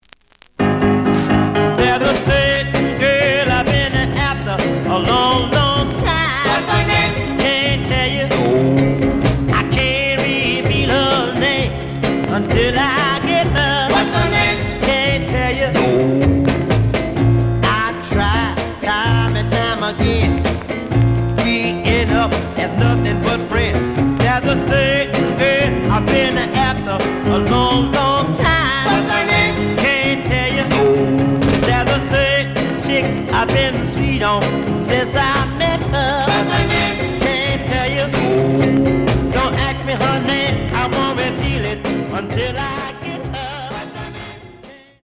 The audio sample is recorded from the actual item.
●Genre: Rhythm And Blues / Rock 'n' Roll
Some noticeable noise on both sides.